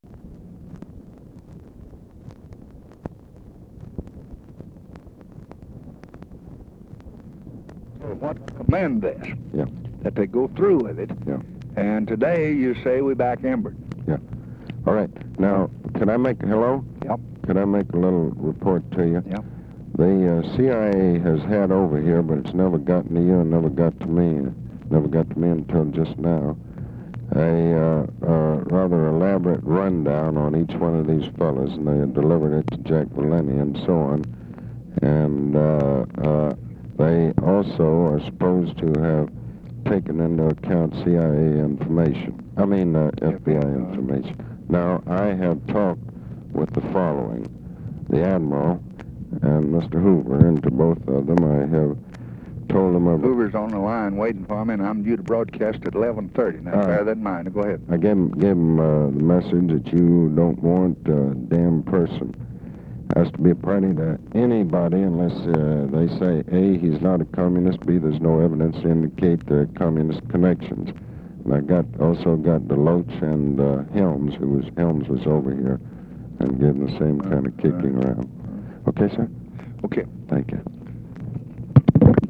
Conversation with ABE FORTAS, May 19, 1965
Secret White House Tapes